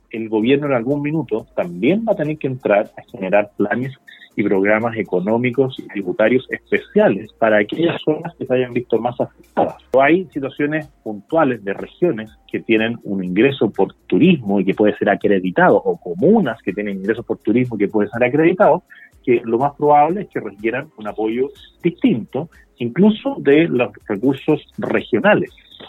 En entrevista con el programa Haciendo Ciudad de Radio Sago, el líder del Partido Republicano, José Antonio Kast, se refirió a la situación actual que vive hoy en día Chile en medio de la pandemia por coronavirus.